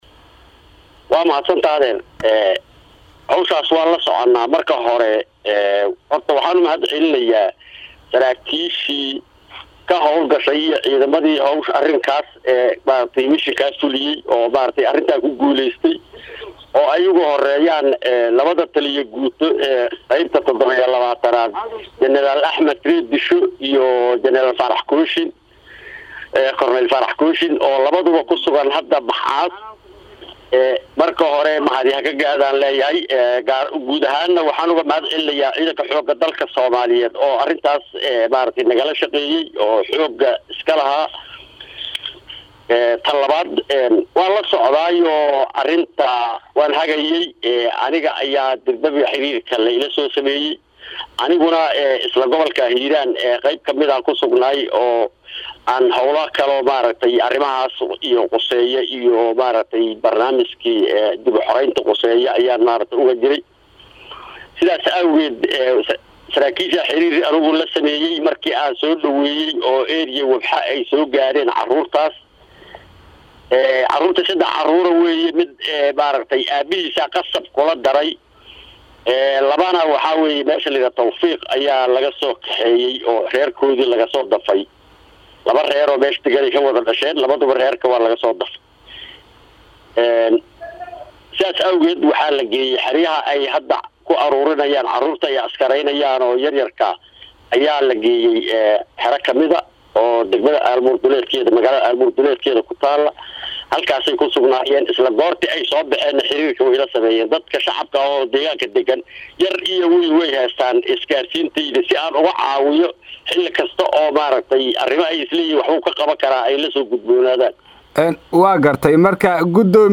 DHAGEYSO :Gudoomiyah Dagmaada Ceelbuur oo ka hadalay hawlgal logaa soo furtay caruur Al-shabaab
Halkaan ka dhageyso codka Gudoomiyaha Dagmaada Ceel Buur